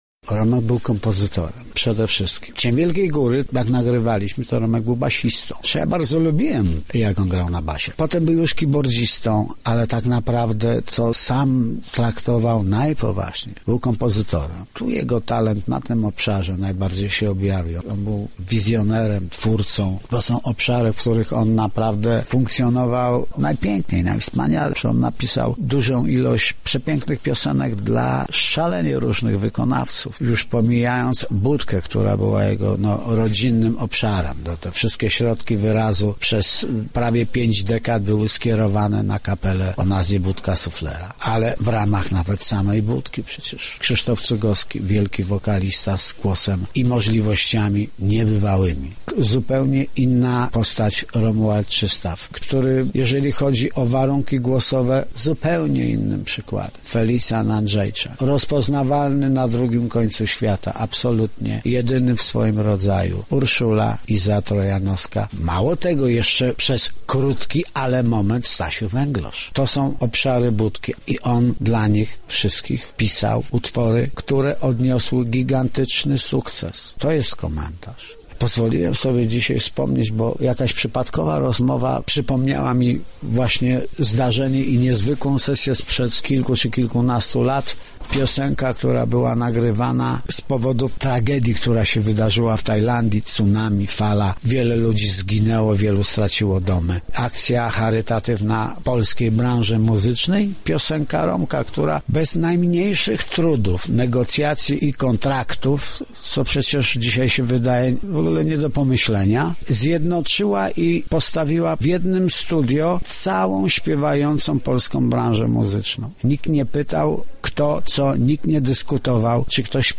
Swoje wspomnienia o Romualdzie Lipce przekazali nam także koledzy z Budki Suflera. Jednym z nich jest Tomasz Zeliszewski, perkusista oraz manager zespołu.